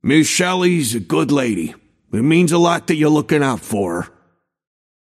Shopkeeper voice line - Miss Shelly’s a good lady.
Shopkeeper_hotdog_t4_bebop_02.mp3